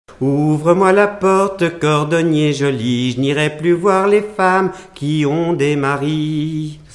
Genre laisse
Chansons traditionnelles
Pièce musicale inédite